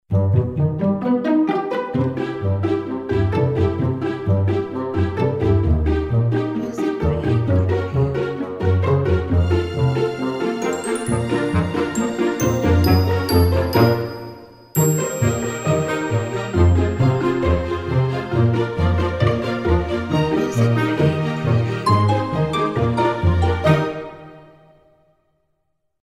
Tempo (BPM): 70